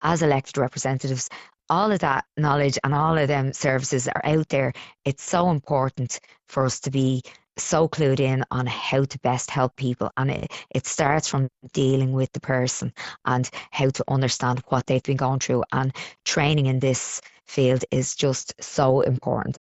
Senator Costello says most public reps want to help, but don’t always know how: